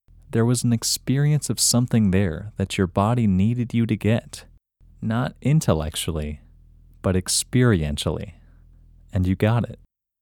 IN – Second Way – English Male 29